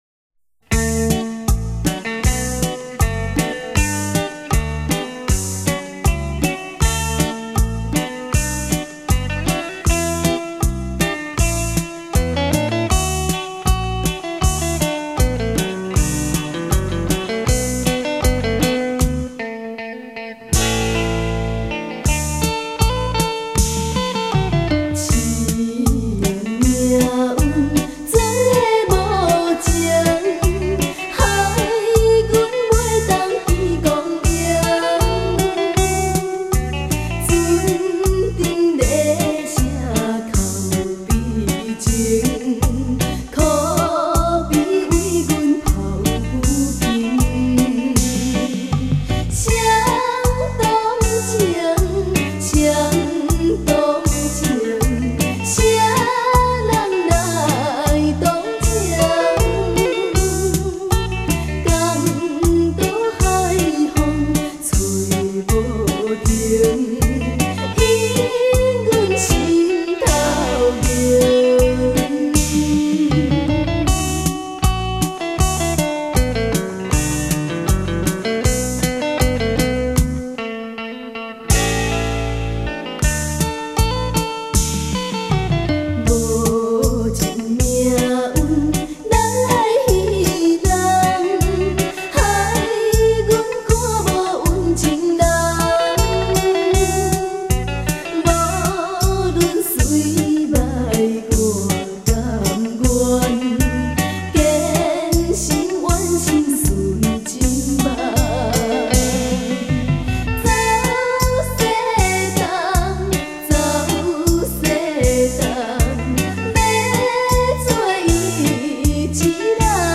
悠扬双吉他声中
悠扬的乐声